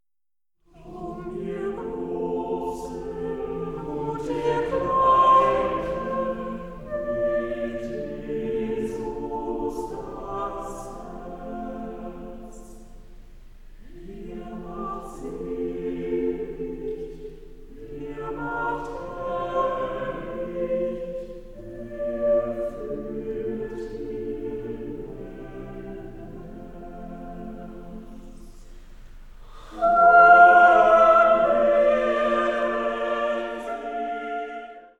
Vokalensemble